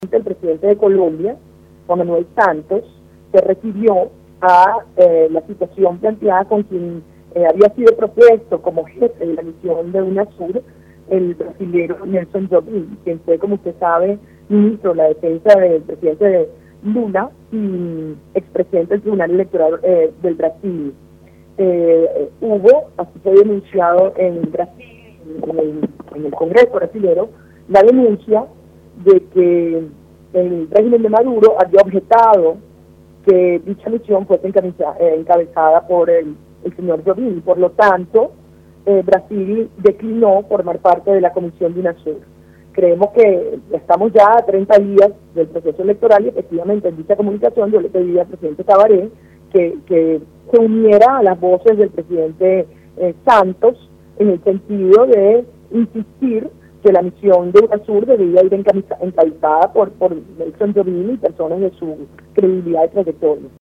En diálogo con Espectador al día la legisladora relató que Brasil declinó formar parte de dicha comisión porque el presidente Nicolás Maduro cuestionó al representante de ese país.